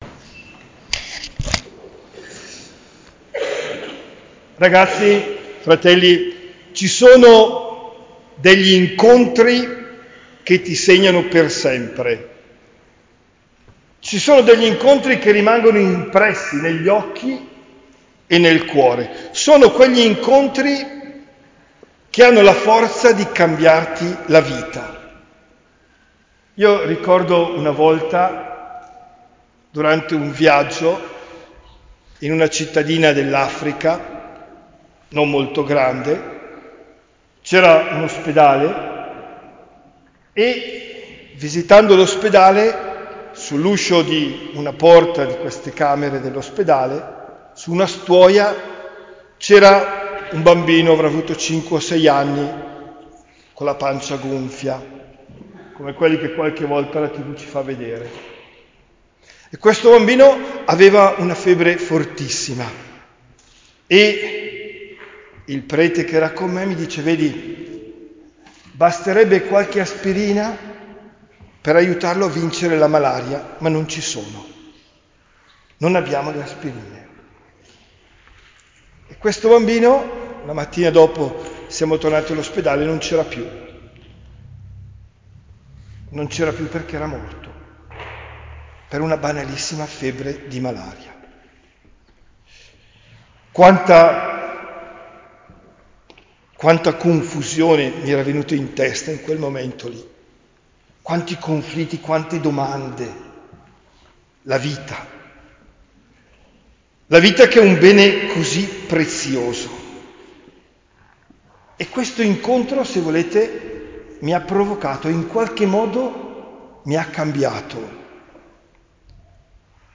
OMELIA DEL 12 MARZO 2023